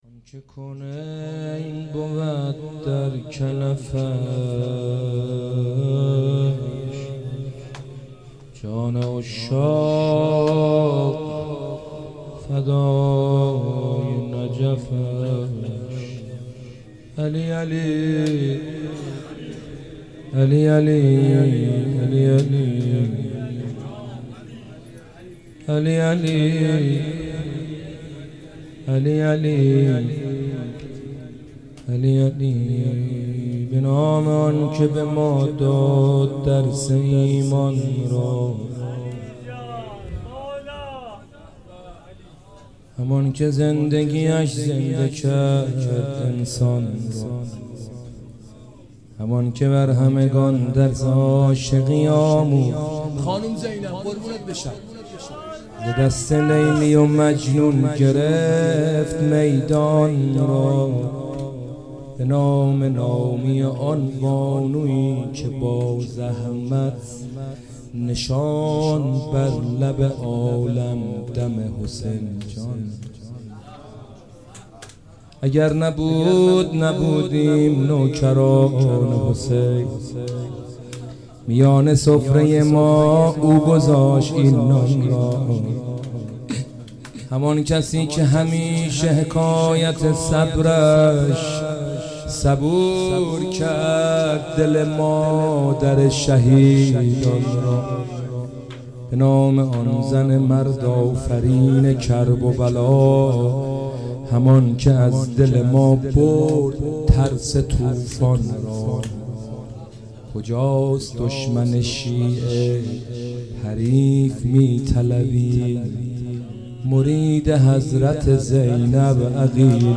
صوت مداحی سیب‌سرخی در دومین شب از مراسم عزاداری هیات روضه العباس (ع) منتشر شد.
صوت مداحی سیب‌سرخی در شب دوم این مراسم، قابل شنیدن و دریافت از پیوست خبر است.